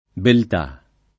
belt#+] s. f. — note alla tradiz. poet. numerose varianti: biltà [bilt#+] e bieltà [bLelt#+], biltate [bilt#te] e bieltate [bLelt#te], tutte usate da Dante, non sopravvissute più in qua del ’300 o del ’400; beltate [belt#te] e più ancóra beltade [belt#de], rimaste in uso fin nel pieno dell’età moderna, tutt’e due fino all’800